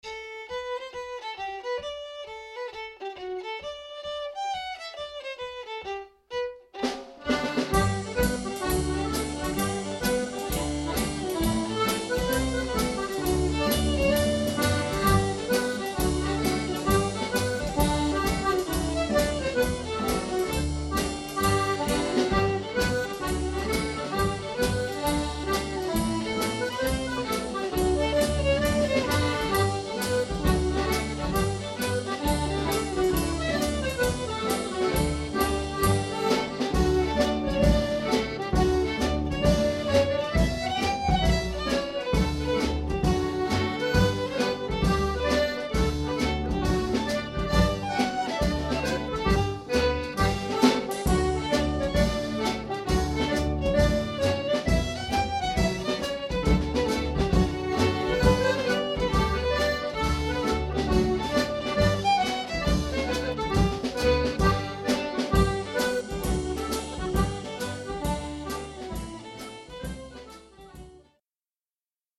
6 x 32 bar hornpipes